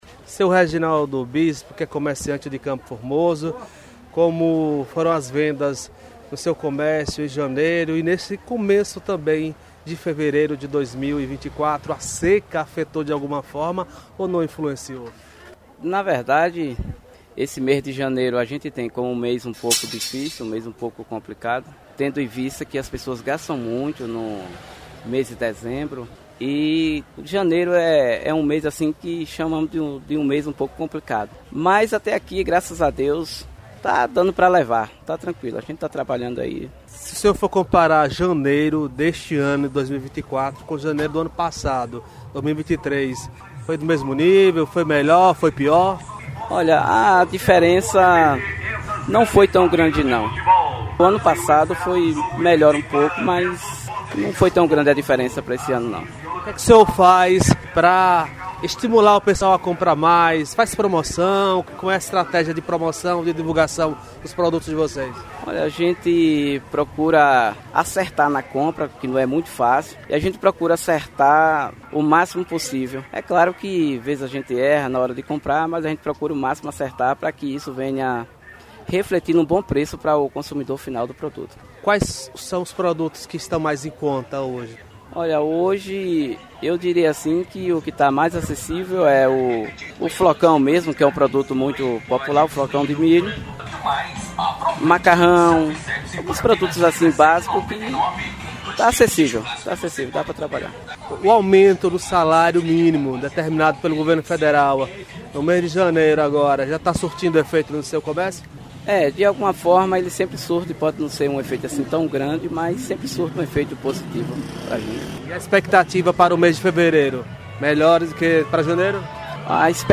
Comerciantes de CFormoso, do segmento de gêneros alimentícios falam da queda na vendas no mês de janeiro